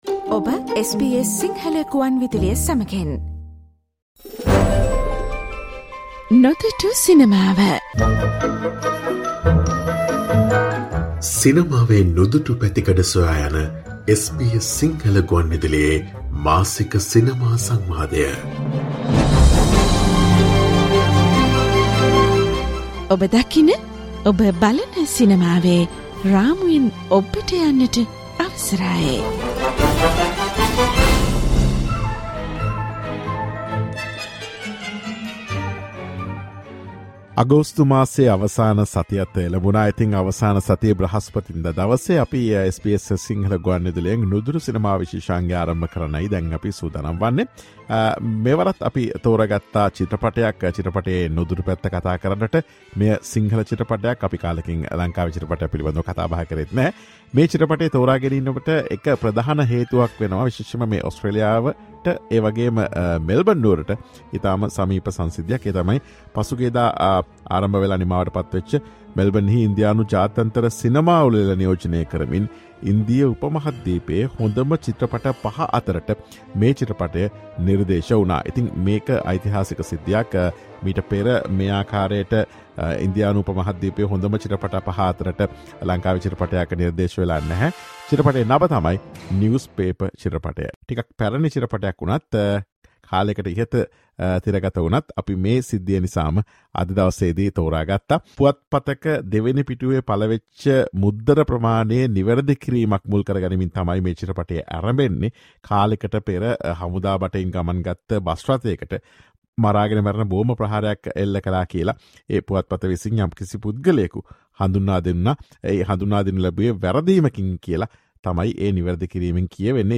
SBS Sinhala